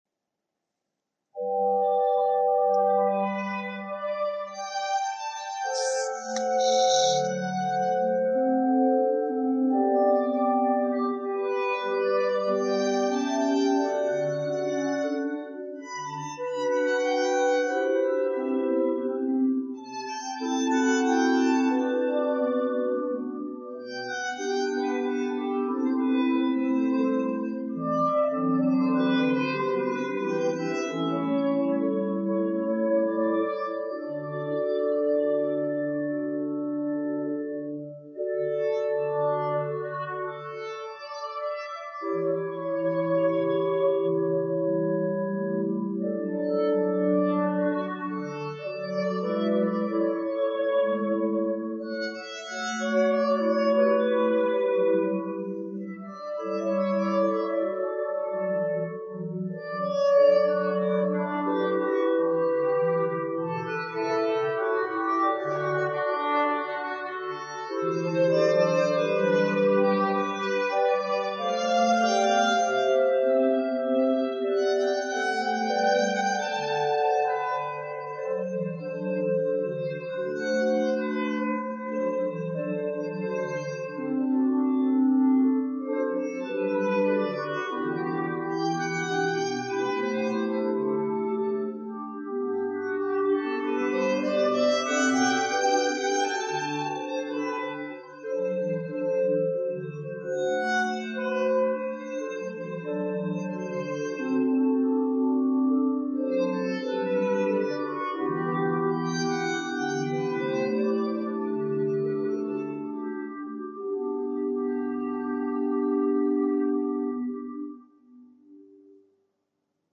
Da pfeift’s mir die Ohren weg
Wir sind rechtzeitig zum abendlichen Orgelkonzert in der Heilig-Geist-Kirche und genießen die überwältigende Akustik, mit deren Unterstüztung die einsame Geige im Duett mit den vielen Pfeifen der Orgel Sonaten von Domenico Scarlatti singt: